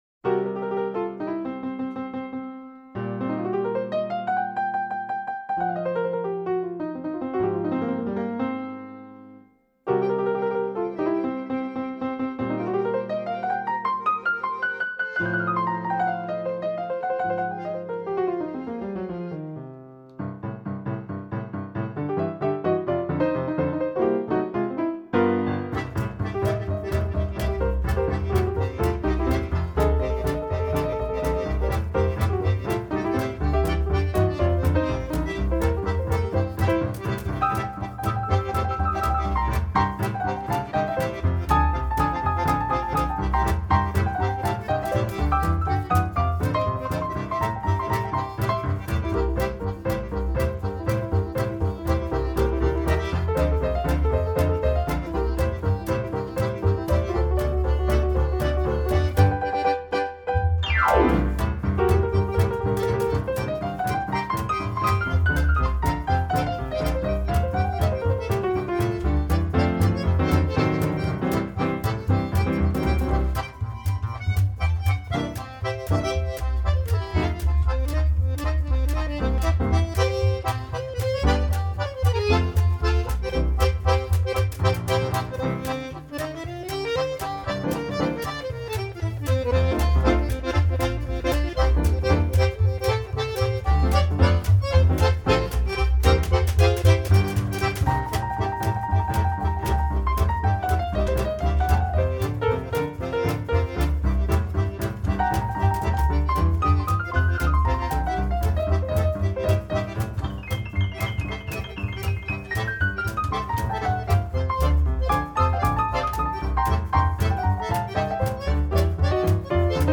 aufgenommen 1996 im Tonstudio